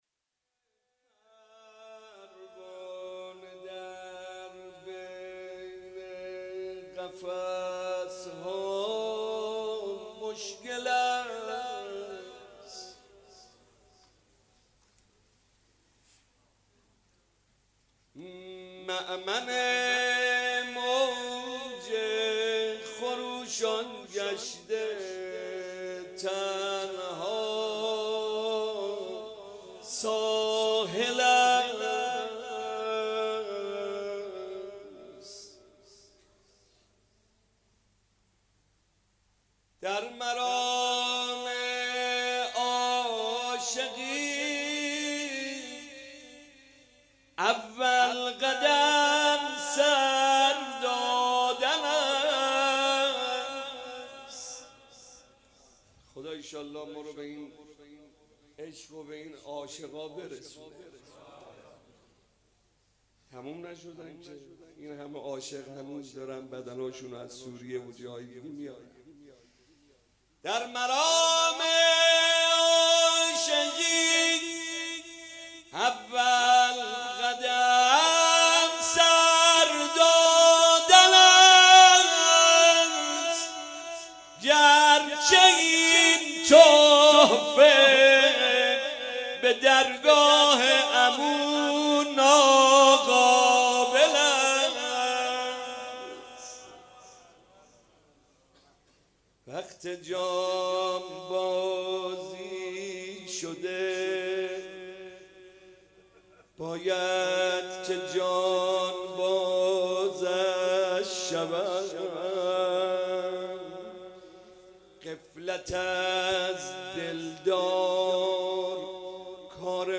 روضه
روضه مناسبت : شب پنجم رمضان سال انتشار